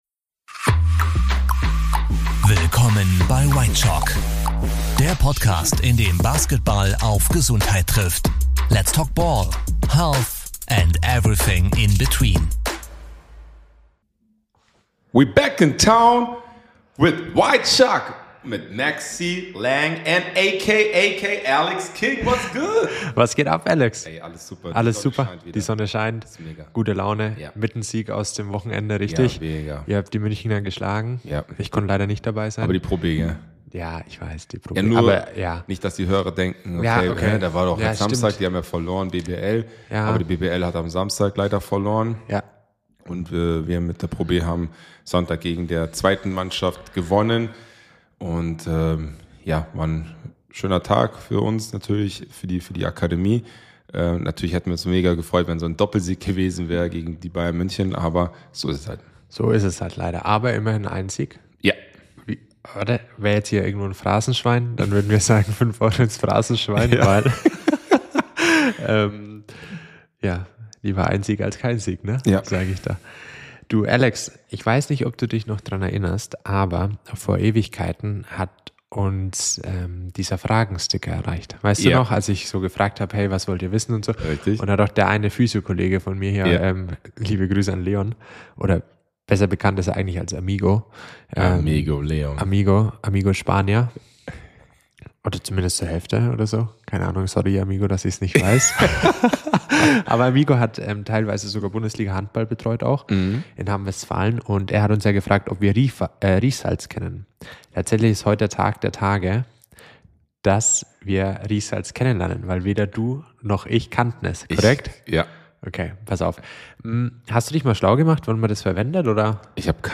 Zwei echte Profis, ein Mikro und jede Menge Insights!
Locker, humorvoll und trotzdem informativ – Whitechoc bietet spannende Anekdoten, Expertenwissen und echte Einblicke in die Welt von Profisport und Sporttherapie.